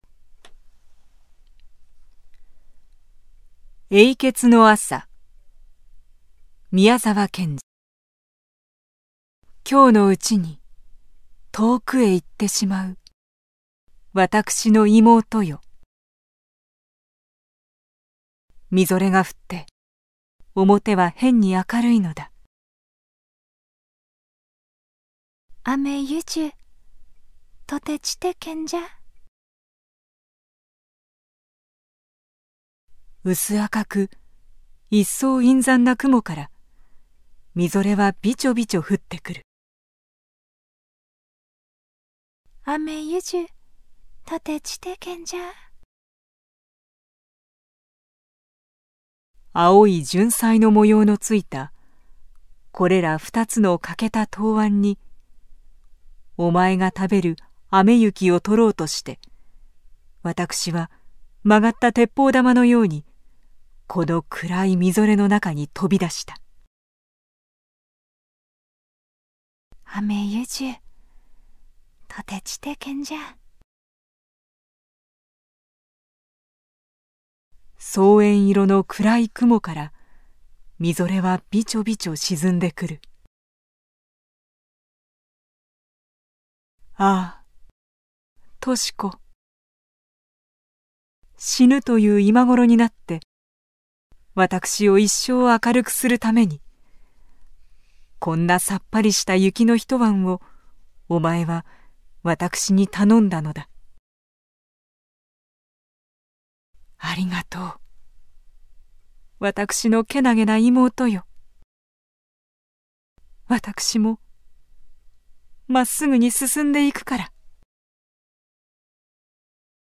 朗 読
※一部の作品には、現在において不適切と思われる表現が含まれている場合がありますが、 原作の内容を尊重し、原作通り朗読させていただいております。